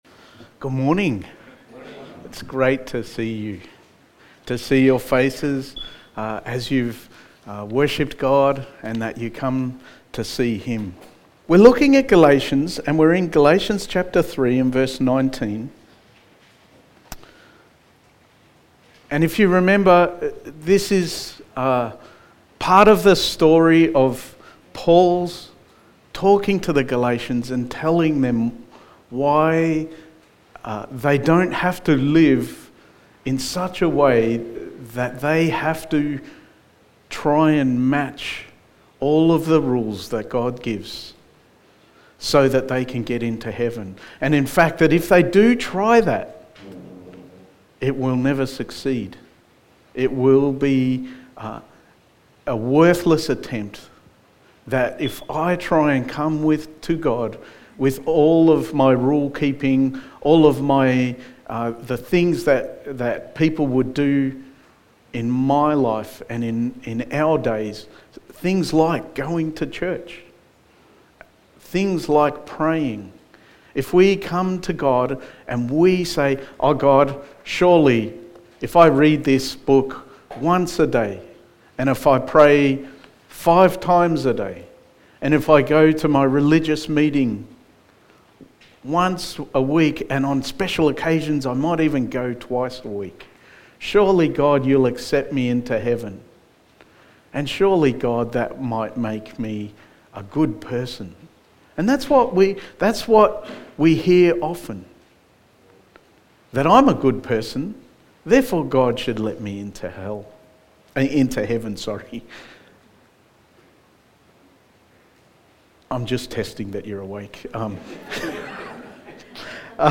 Sermon
Galatians Series Passage: Galatians 3:19-24 Service Type: Sunday Morning Sermon 10 « People Who Misses Christmas and Why?